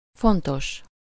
Ääntäminen
Synonyymit important conséquent grand majeur Ääntäminen France: IPA: [kɔ̃.si.de.ʁabl] Haettu sana löytyi näillä lähdekielillä: ranska Käännös Ääninäyte Adjektiivit 1. fontos Suku: f .